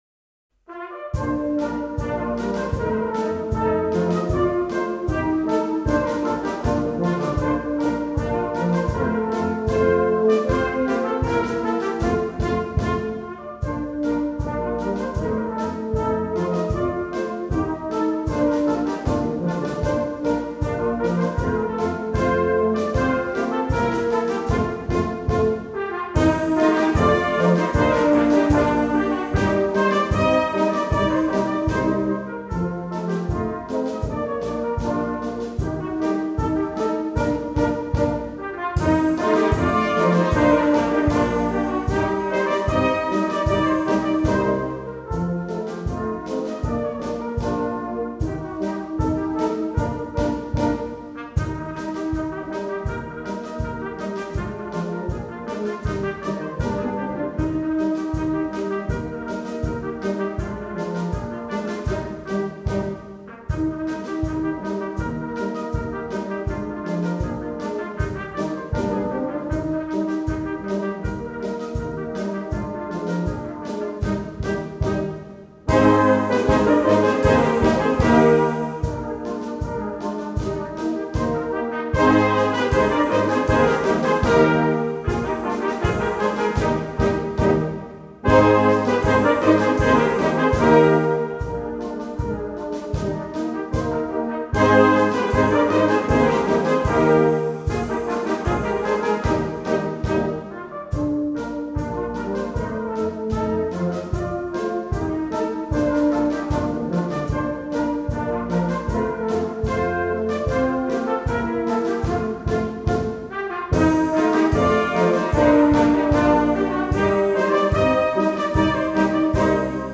Rentrée Tardive: Pervenche (Schottisch)
Western Switzerland.